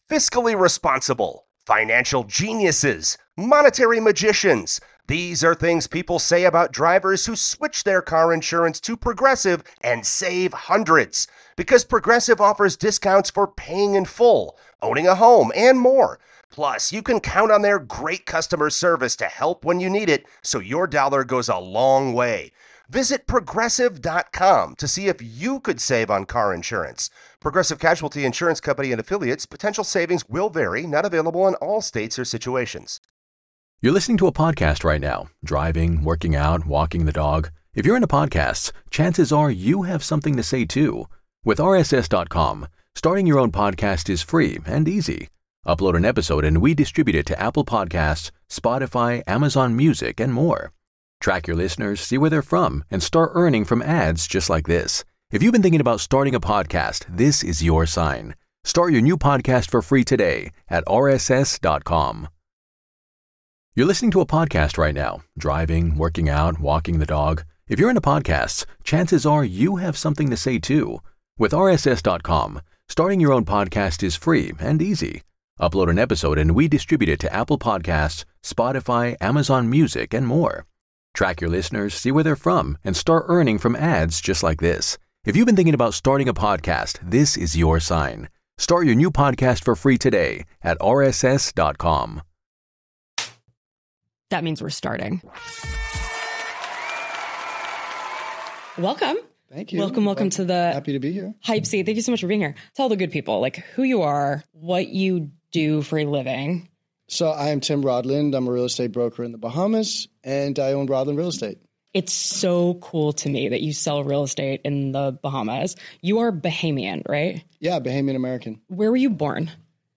📍 Recorded at PodPlus Studios in Jupiter, Florida